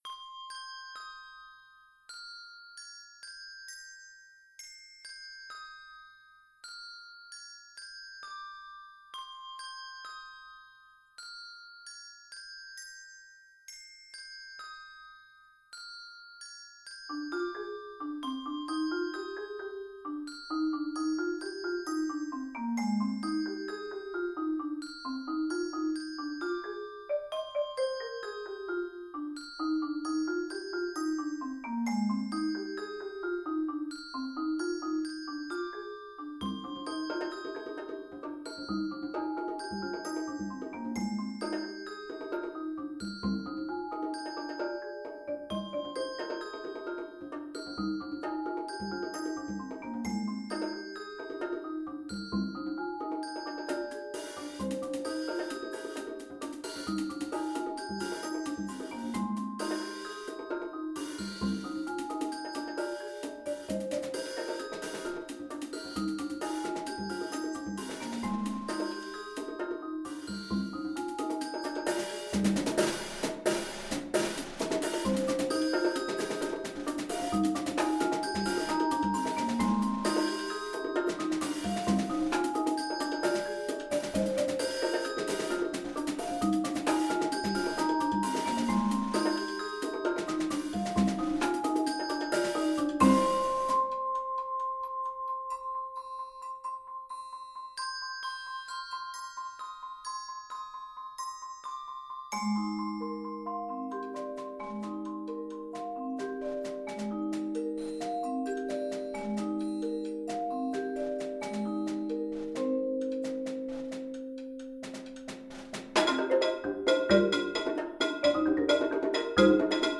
Genre: Percussion Ensemble
# of Players: 5
Player 3: Desk Bells (D5, Ab5), 2 Congas, Bongos
Player 4: Desk Bells (F5, Bb5), Snare Drum